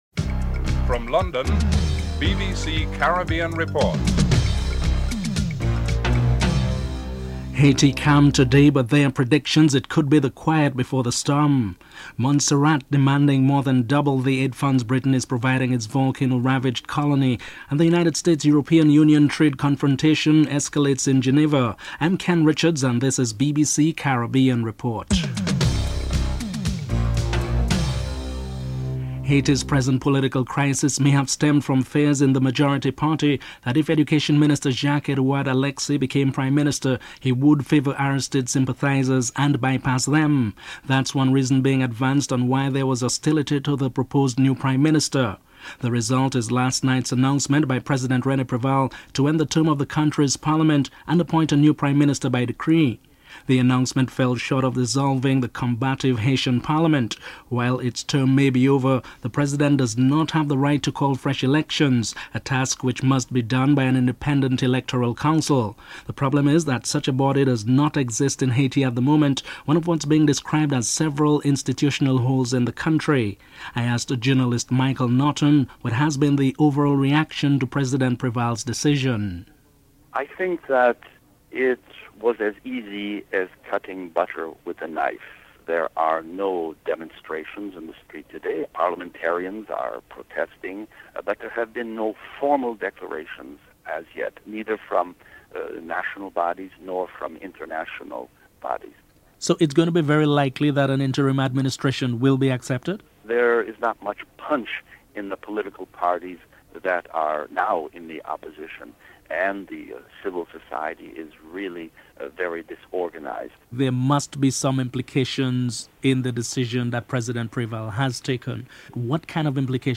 1. Headlines (00:00-00:27)
In an interview Chief Minister Brandt pointed out that if the families of the deceased applied for compensation, there will be no money because even the £75 million allocated to Montserrat by Great Britain for development is insufficient for the needs of devastated Montserrat (06:21-10:23)